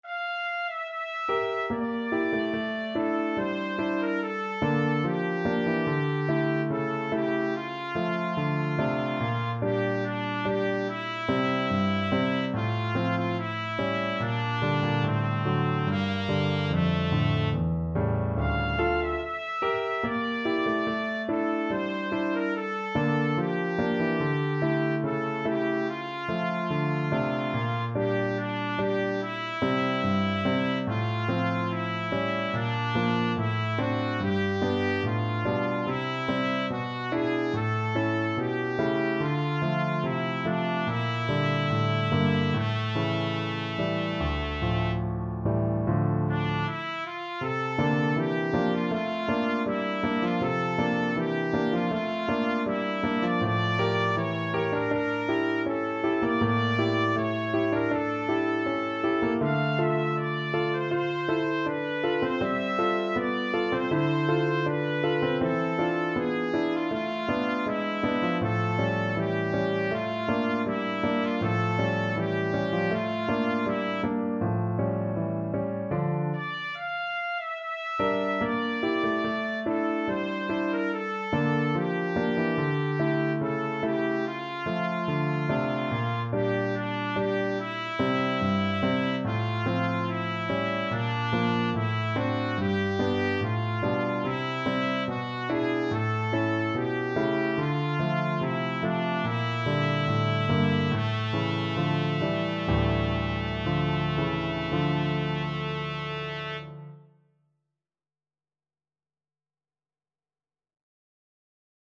~ = 72 Andantino (View more music marked Andantino)
2/4 (View more 2/4 Music)
Classical (View more Classical Trumpet Music)